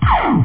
Amiga 8-bit Sampled Voice
plasma.mp3